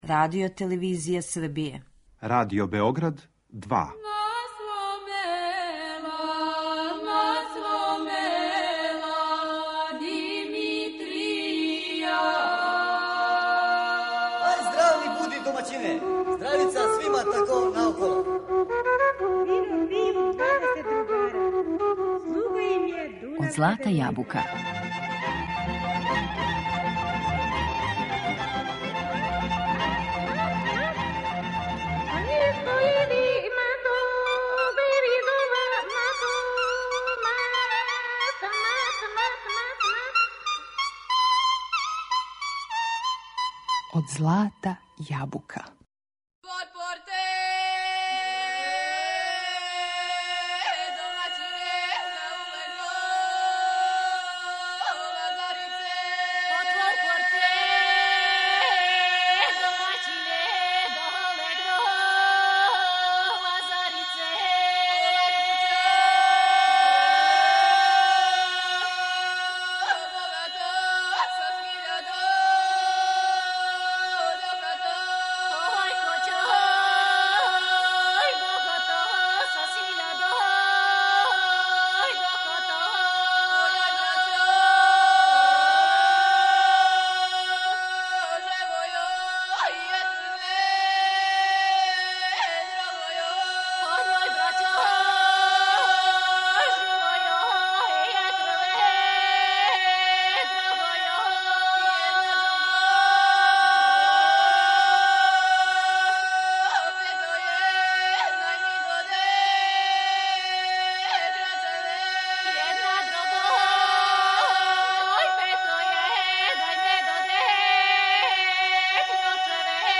Како се данас жене баве извођењем, неговањем и обогаћивањем српске вокалне традиције? У емисији ће бити представљене изворне професионалне групе, али и групе које делују у оквиру културноуметничких друштава.